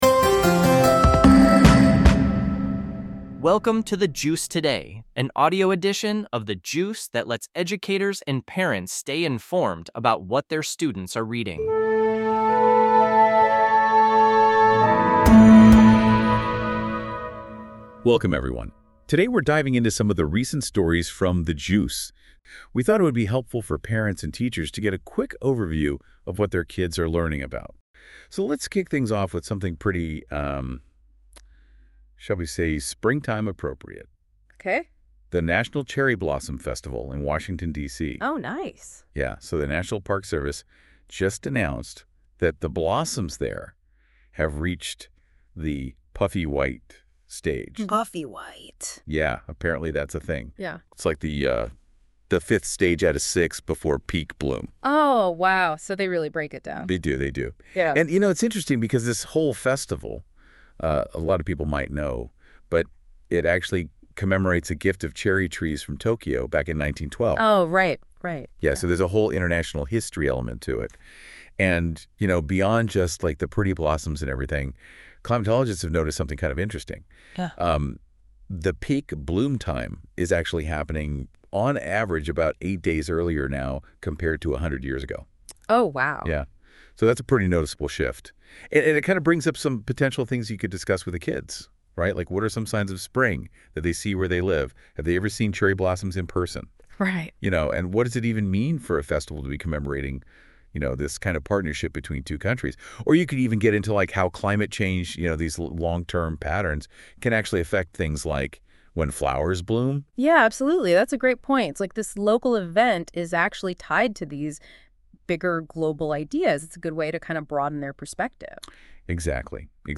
This podcast is produced by AI based on the content of a specific episode of The Juice.